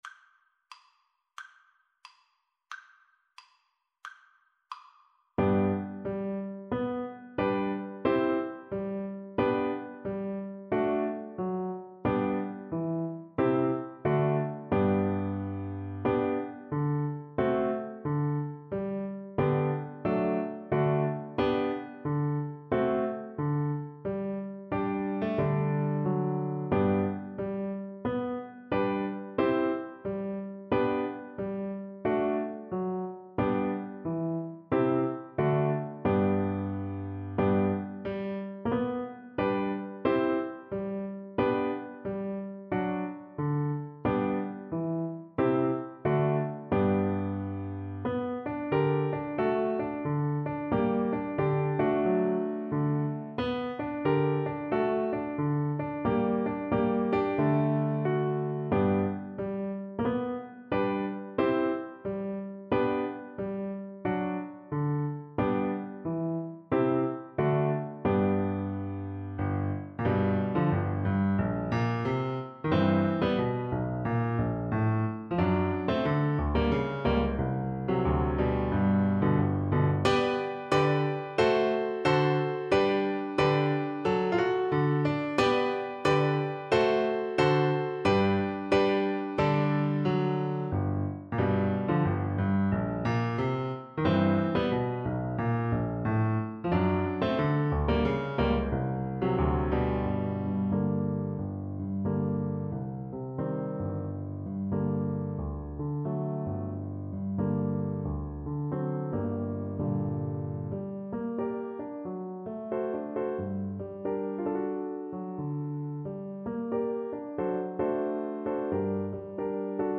2/4 (View more 2/4 Music)
THEME: Allegro =c.120 (View more music marked Allegro)
Flute  (View more Intermediate Flute Music)
Jazz (View more Jazz Flute Music)
Rock and pop (View more Rock and pop Flute Music)